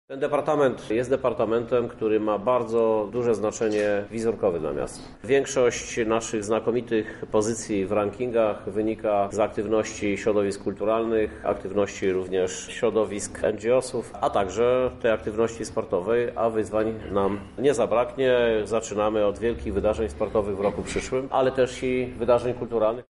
O zadaniach Beaty Stepaniuk-Kuśmierzak mówi prezydent Krzysztof Żuk.